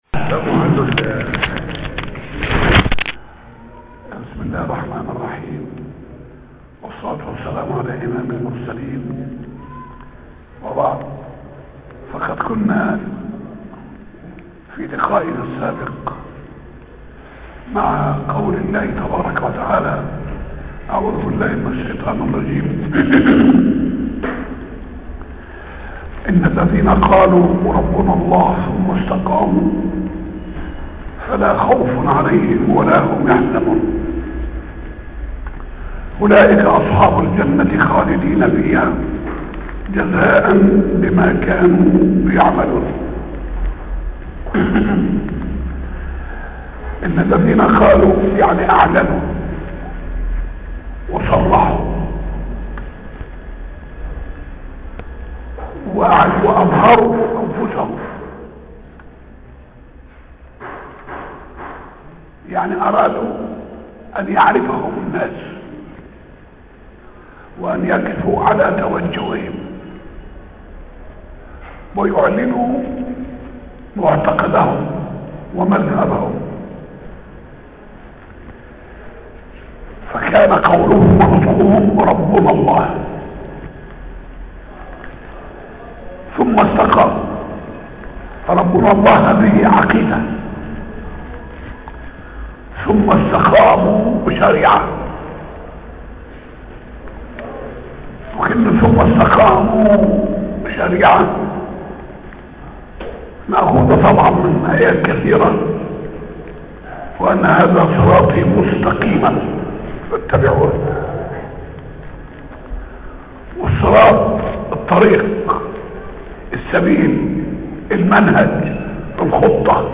موضوع: سورة الاحقاف - مسجد السلام تفسير الاحقاف 1